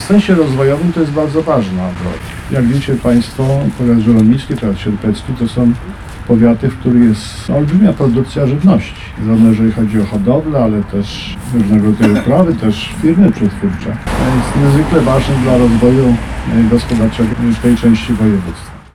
Oficjalne otwarcie rozbudowanej ul. Głowackiego w Sierpcu
– mówił marszałek Adam Struzik.